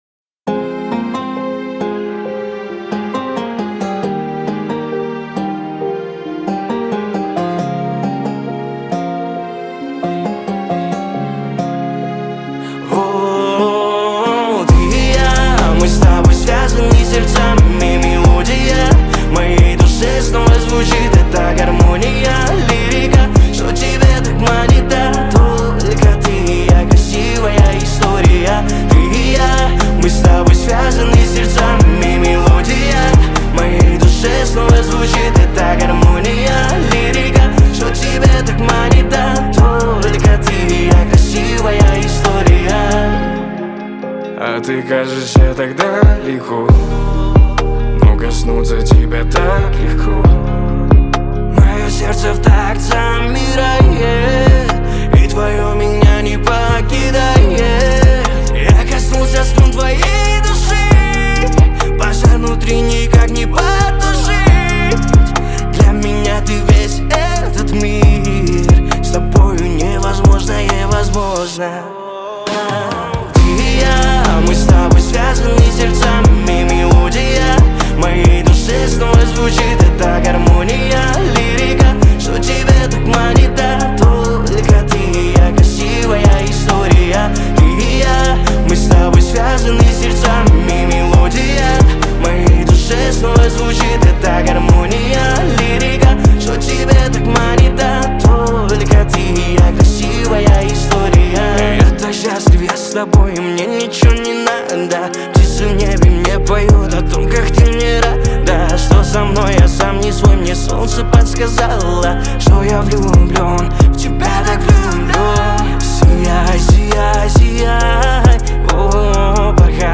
Русские и украинские песни